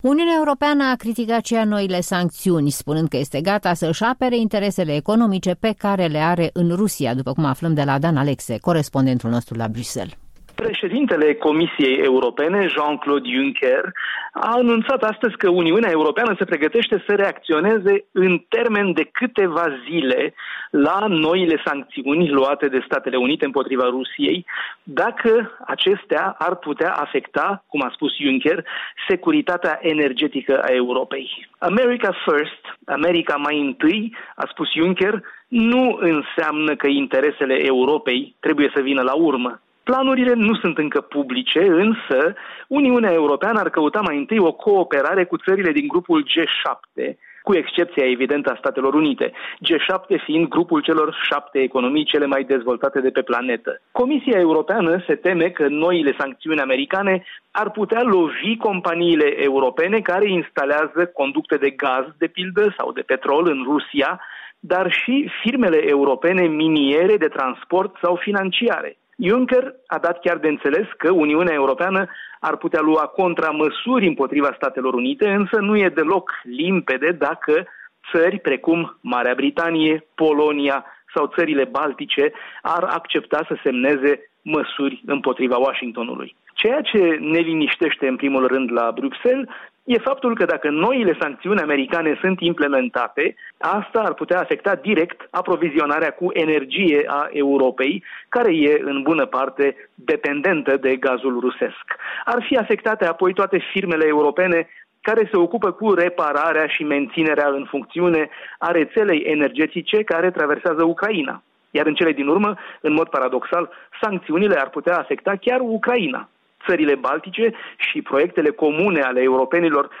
Corespondența zilei de la Bruxelles